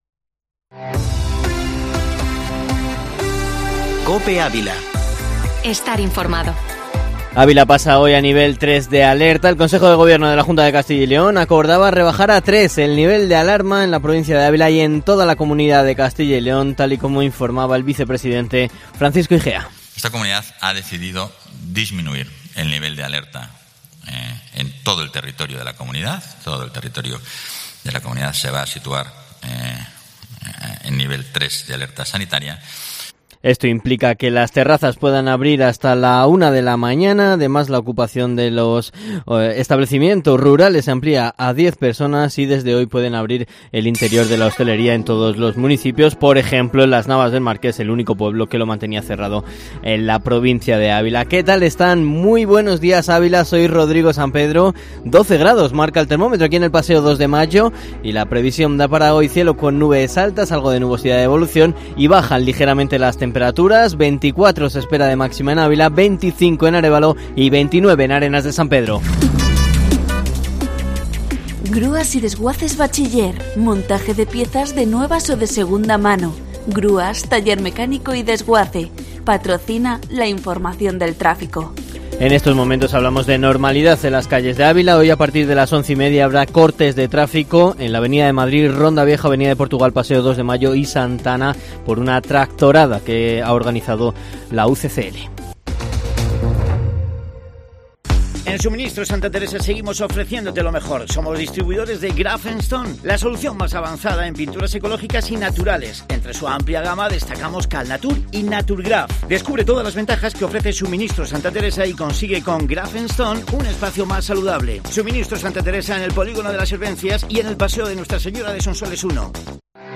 Informativo matinal Herrera en COPE Ávila 21/05/2021